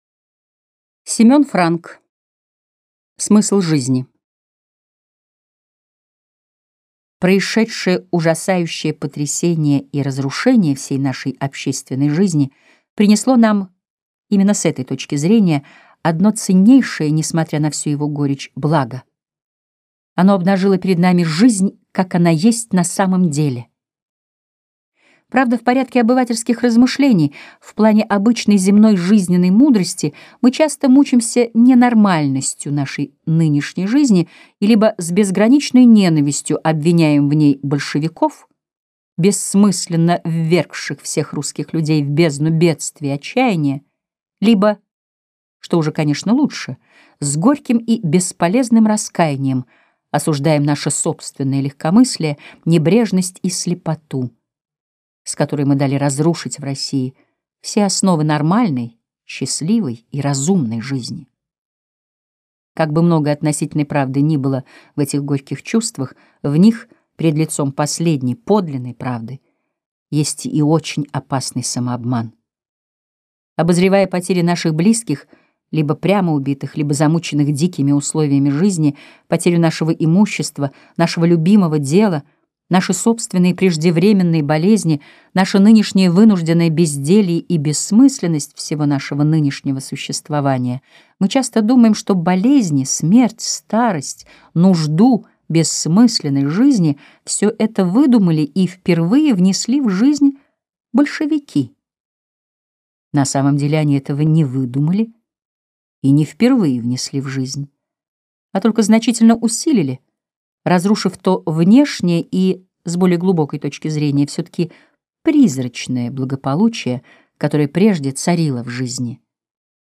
Аудиокнига Смысл жизни | Библиотека аудиокниг